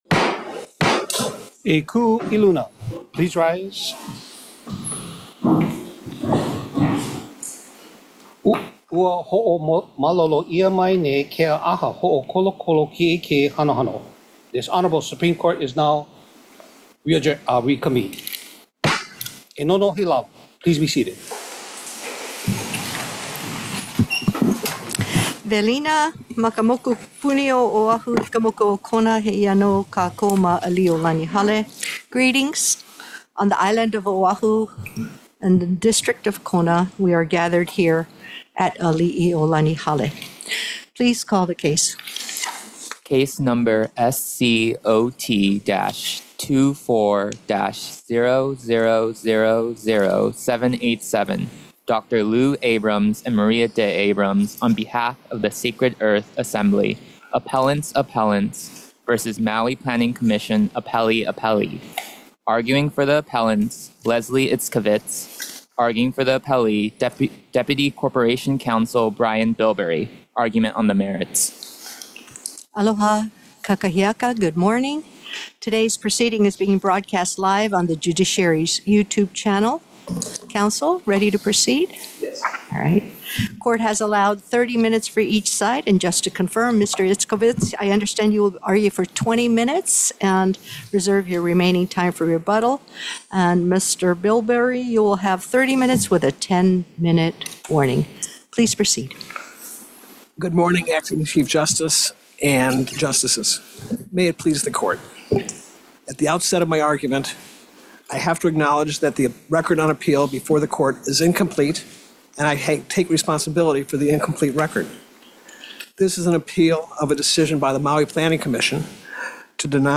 The above-captioned case has been set for oral argument on the merits at: